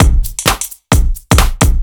OTG_Kit 4_HeavySwing_130-A.wav